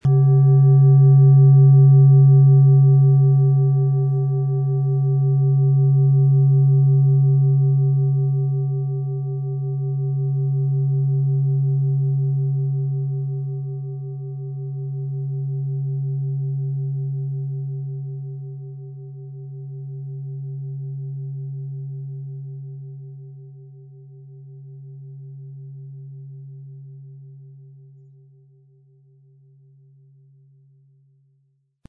Wie klingt diese tibetische Klangschale mit dem Planetenton Biorhythmus Geist?
PlanetentöneBiorythmus Geist & DNA & Mond (Höchster Ton)
MaterialBronze